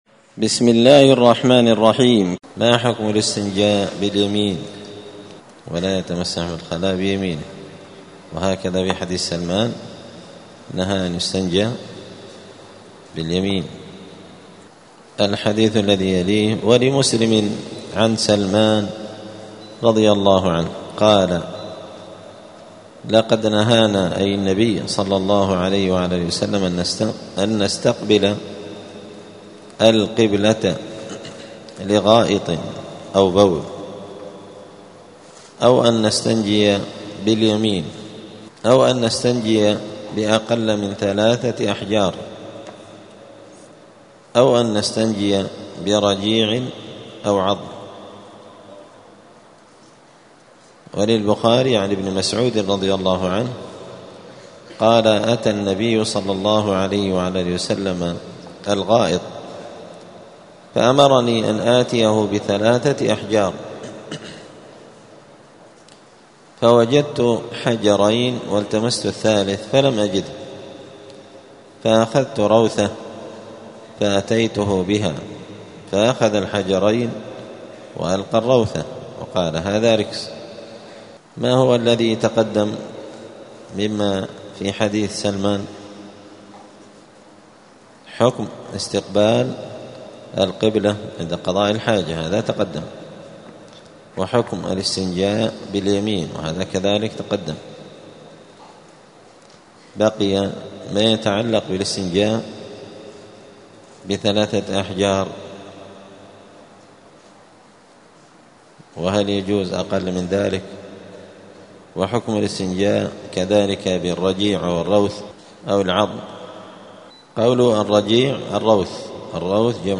دار الحديث السلفية بمسجد الفرقان قشن المهرة اليمن
*الدرس الخامس والستون [65] {باب الاستطابة حكم الاستنجاء بأقل من ثلاثة أحجار}*